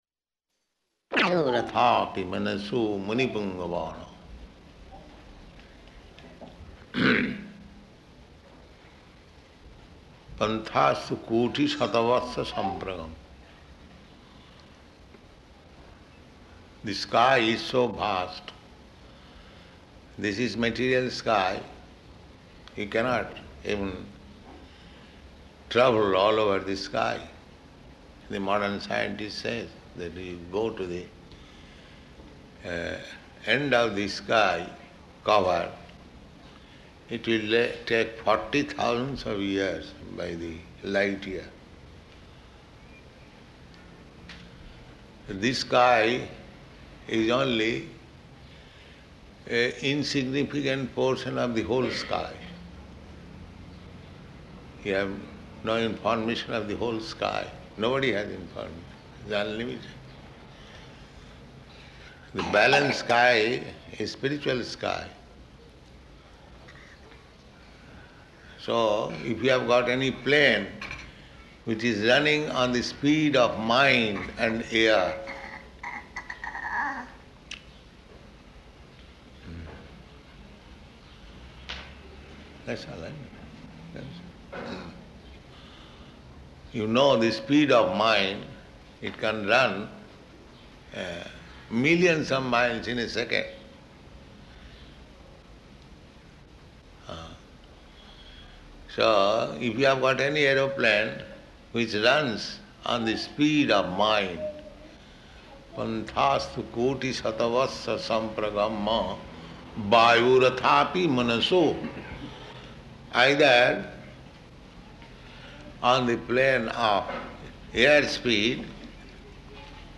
Initiation Lecture
Initiation Lecture --:-- --:-- Type: Initiation Dated: July 28th 1971 Location: New York Audio file: 710728IN-NEW_YORK.mp3 Prabhupāda: ...athāpi manaso muni-puṅgavānām.
[child makes sound] [aside:] That's alright.